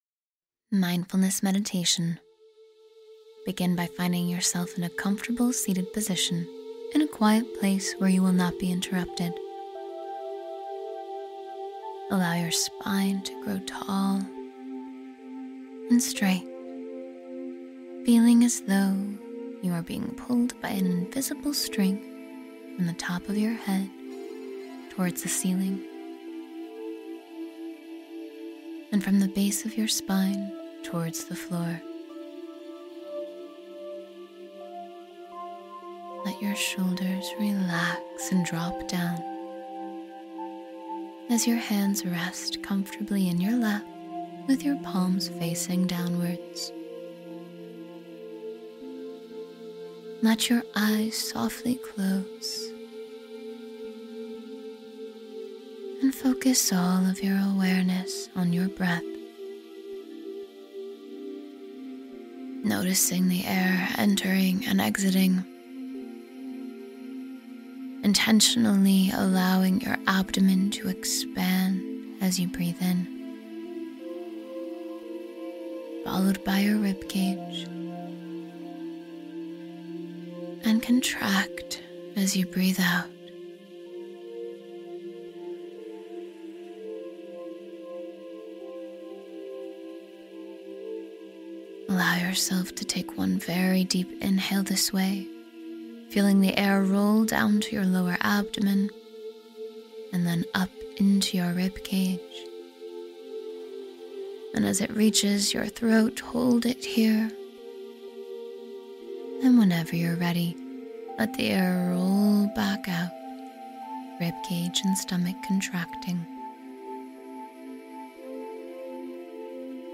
Refresh Your Spirit — 10-Minute Meditation for Instant Peace and Clarity